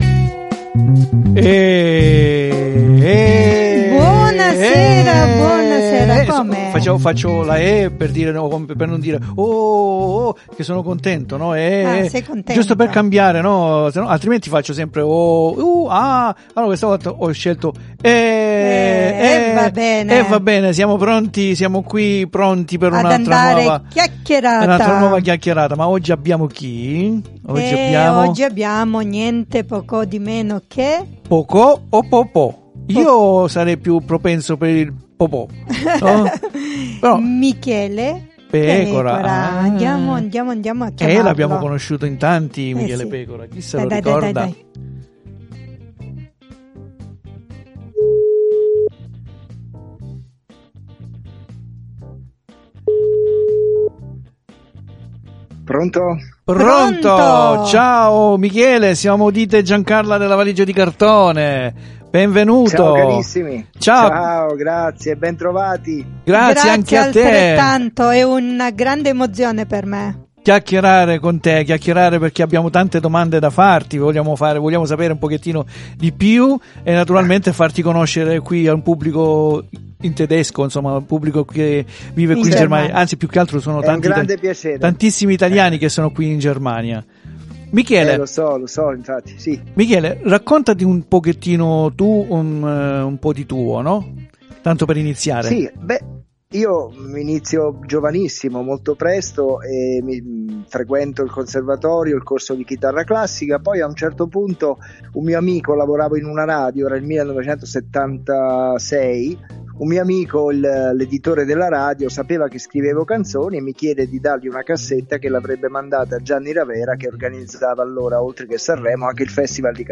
É STATO COMUNQUE UN ONORE PER NOI AVERLO AL TELEFONO, PERSONA MOLTO DISPONIBILE A RACCONTARSI!
78761_INTERVISTA_Michele_Pecora.mp3